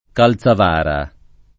DOP: Dizionario di Ortografia e Pronunzia della lingua italiana
Calzavara [ kal Z av # ra ] cogn.